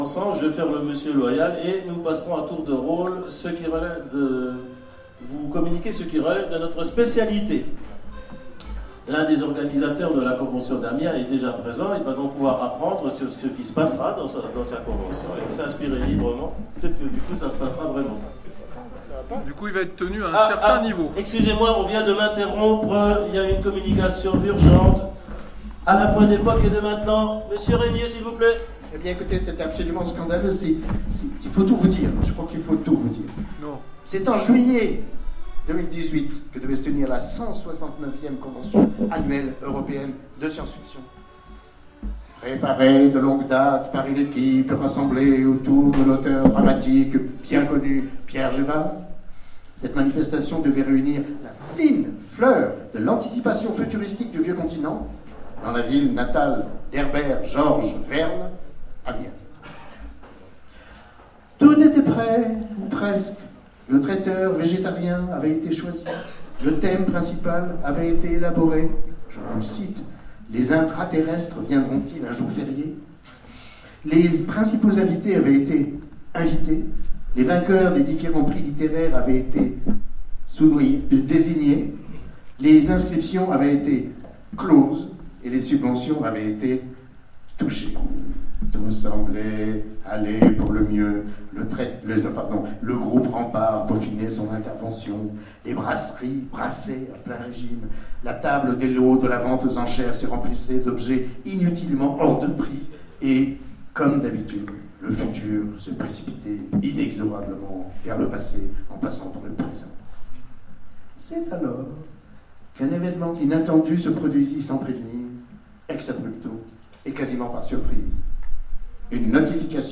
Convention SF 2016 : Conférence Rempart 2018